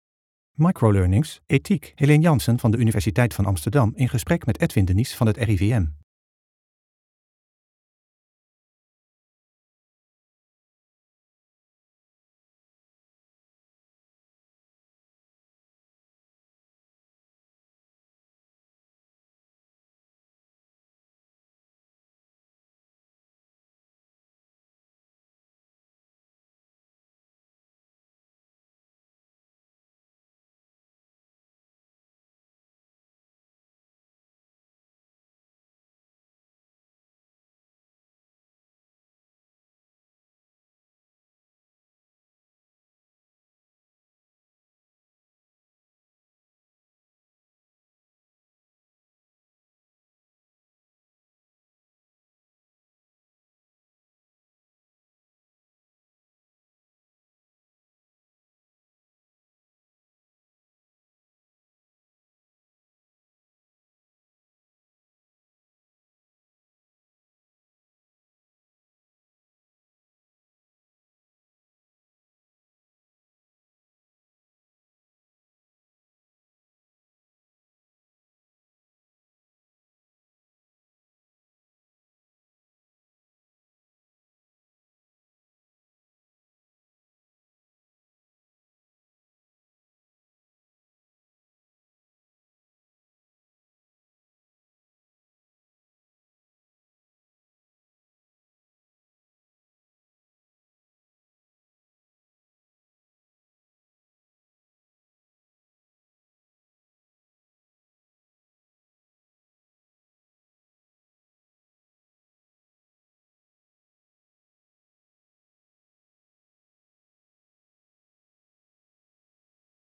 (Intro met abstracte beelden met daaronder een korte begintune.)
(Outro met abstracte beelden en korte eindtune.)